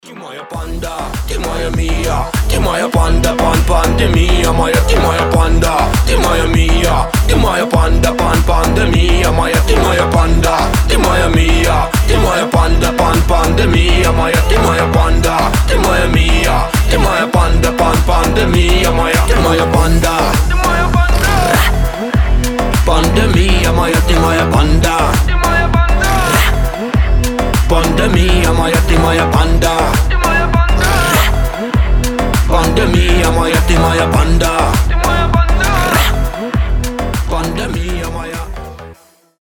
• Качество: 320, Stereo
мощные басы
Bass House
качающие
G-House